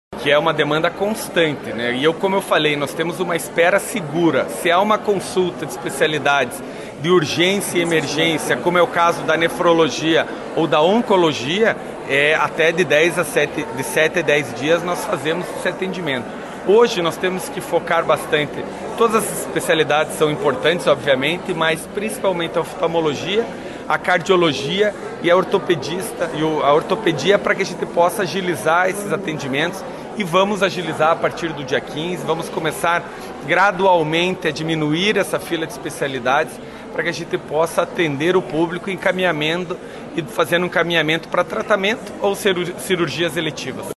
O prefeito Eduardo Pimentel (PSD) lembrou que há uma demanda constante para os exames e lembrou que nos casos de urgências os encaminhamentos são priorizados.